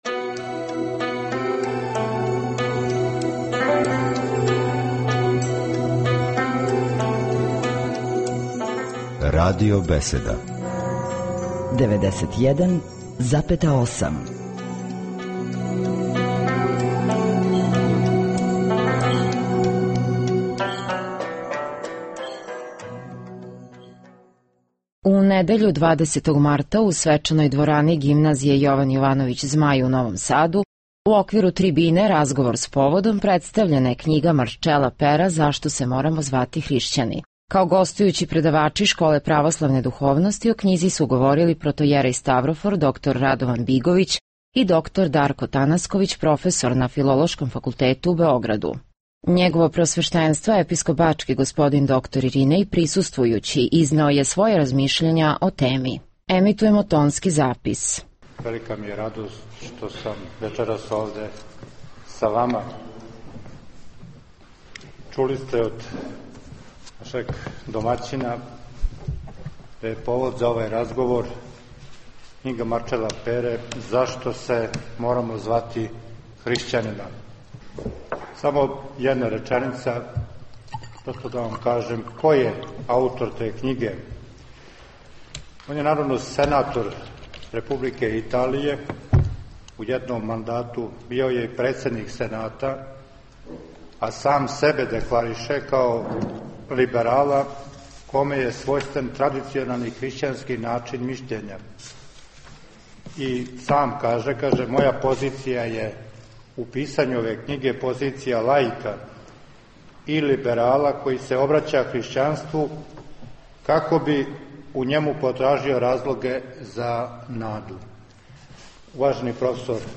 У недељу, 20. марта 2011. године, у свечаној дворани Гимназије Јован Јовановић Змај у Новом Саду, у оквиру трибине Разговор с поводом – Либерализам, Европа, Етика представљена је књига Марчела Пера Зашто се морамо звати хришћани.